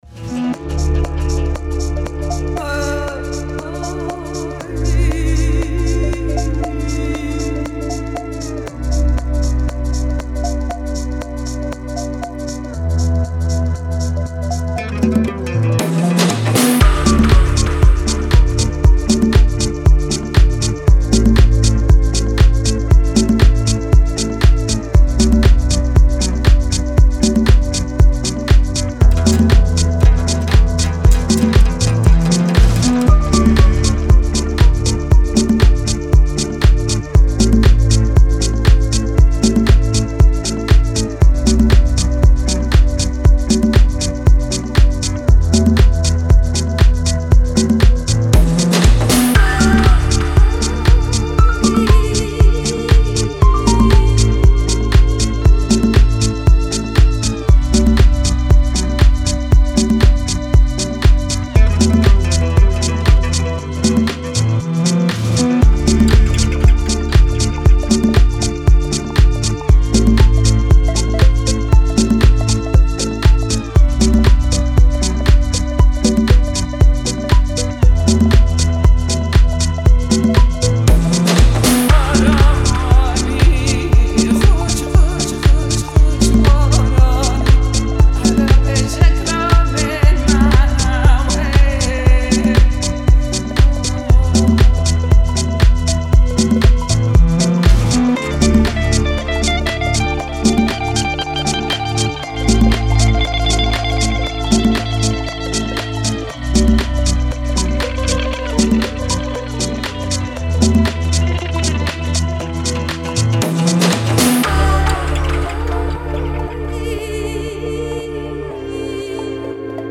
Style: Tech House / Slow House